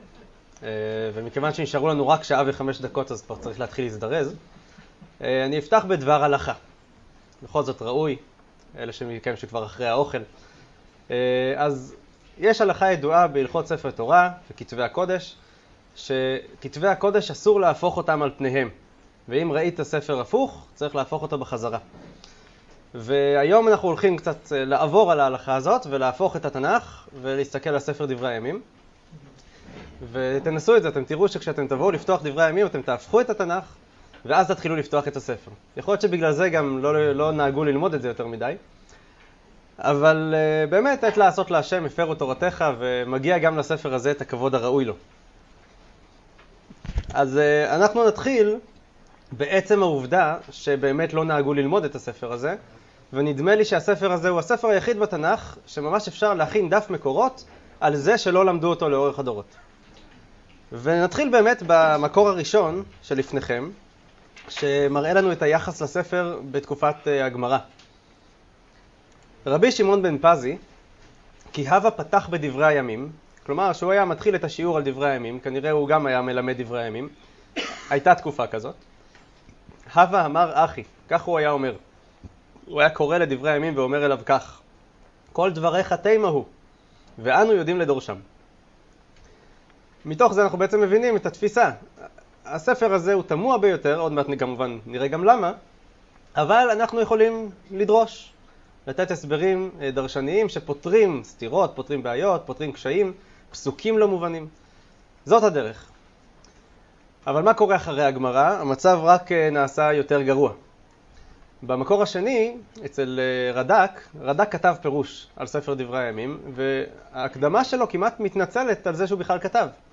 השיעור באדיבות אתר התנ"ך וניתן במסגרת ימי העיון בתנ"ך של המכללה האקדמית הרצוג תשע"ח